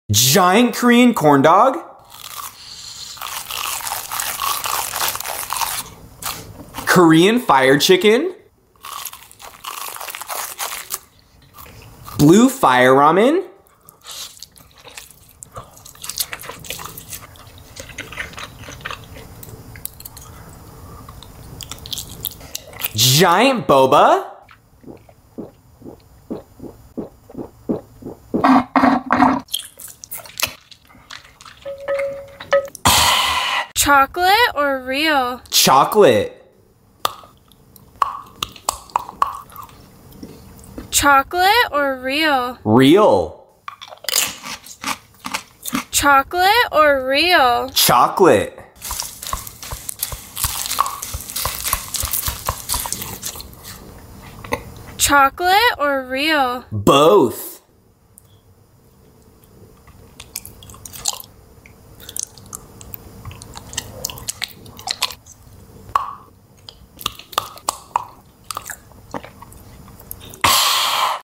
Giant Korean Food ASMR!? 🥵 sound effects free download